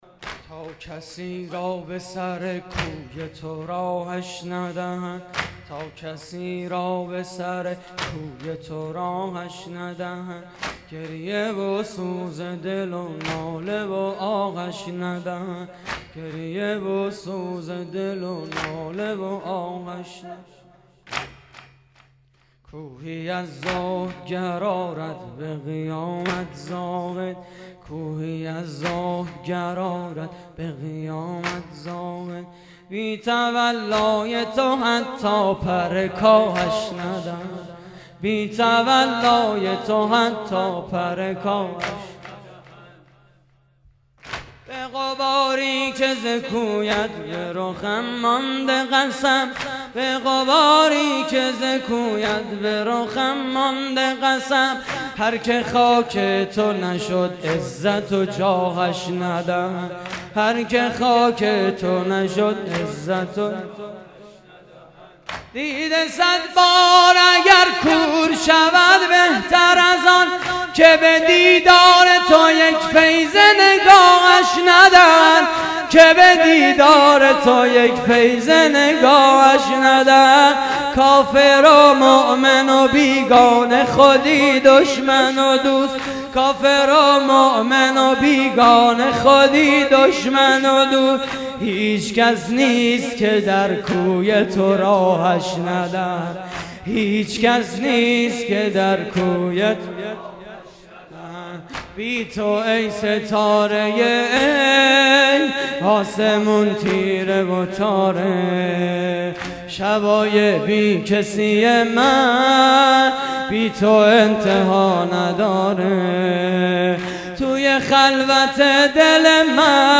واحد: تا کسی را به سر کوی تو راهش ندهند
مراسم عزاداری شب هشتم ماه محرم